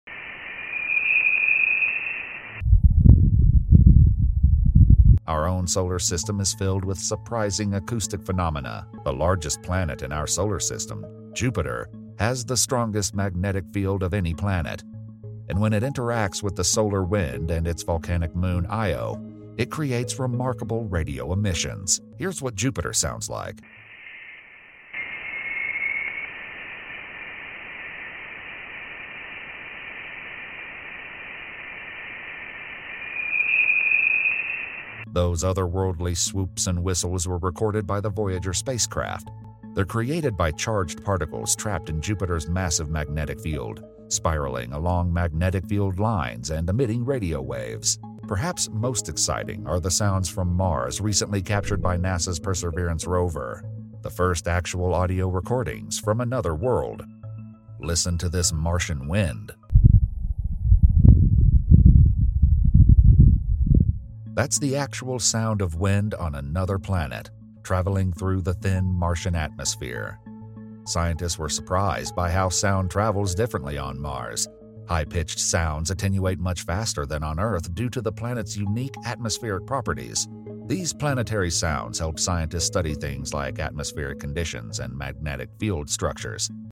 The sound of Jupiter and Mars.